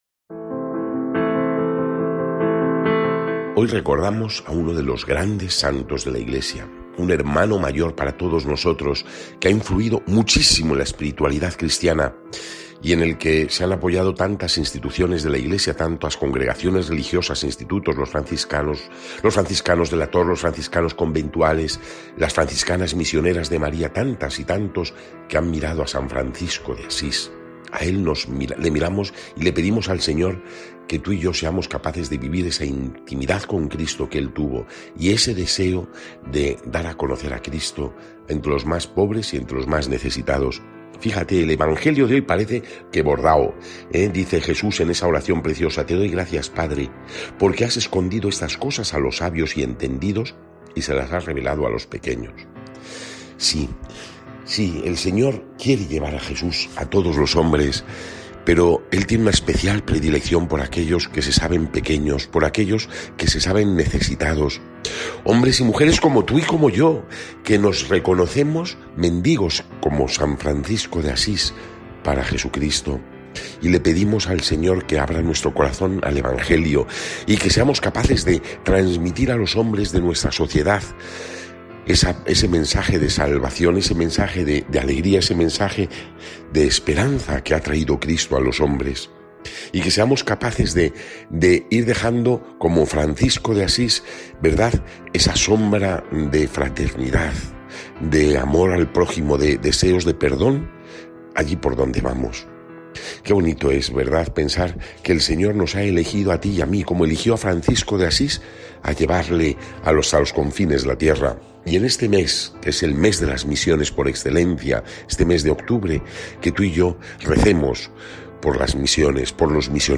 Evangelio según san Lucas (9, 57-62) y comentario